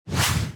Fast Swoosh Sound Effect Free Download
Fast Swoosh